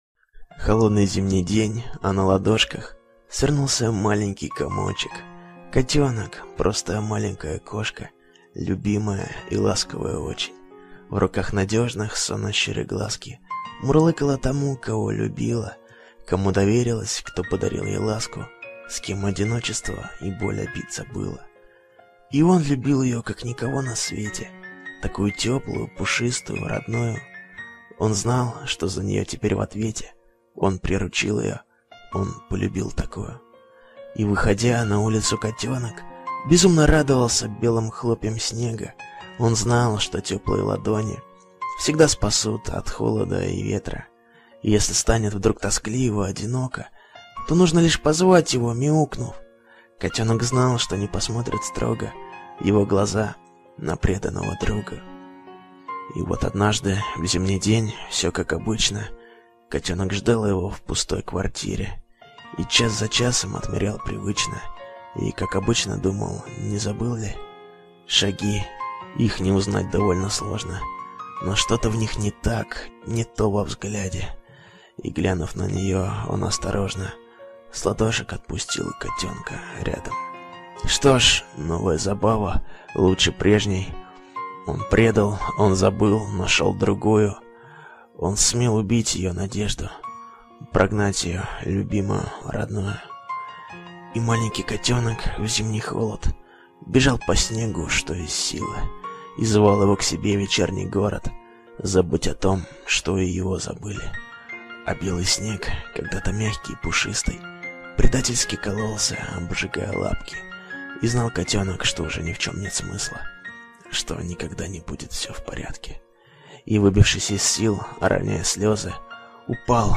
stihi_v_audio_kotenok.mp3